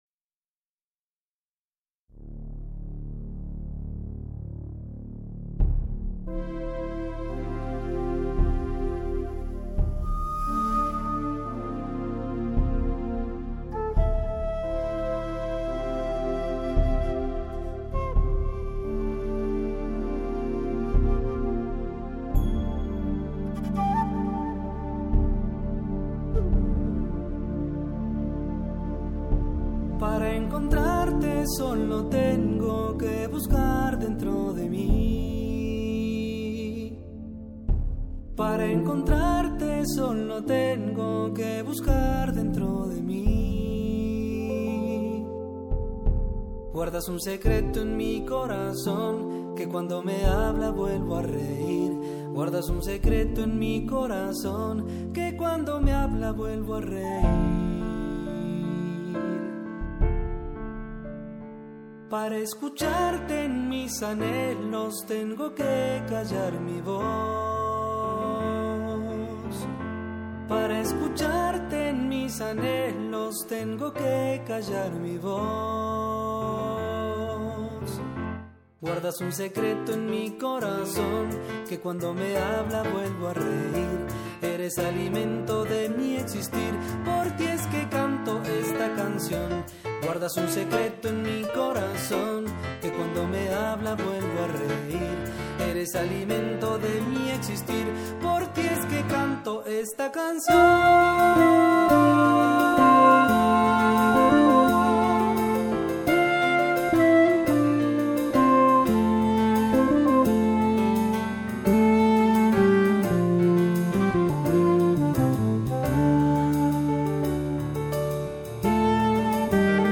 Andes